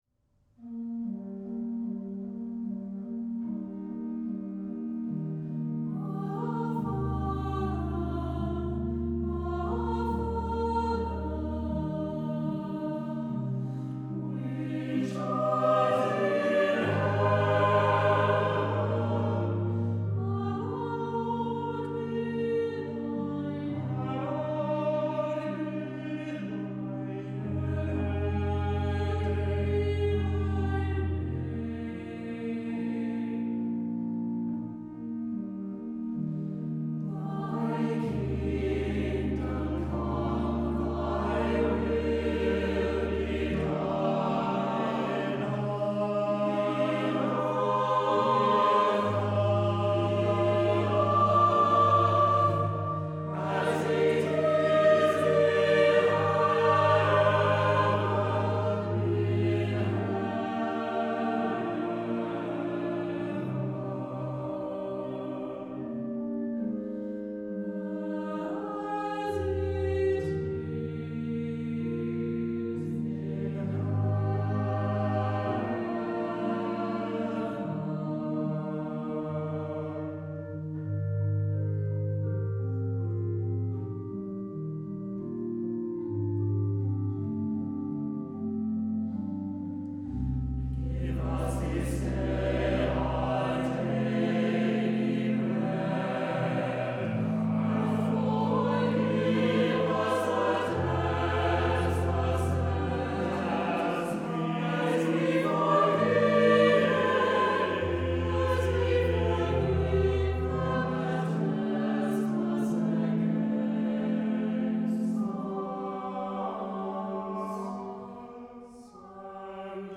LP choral
Music Score for SATB Choirs with organ accompaniment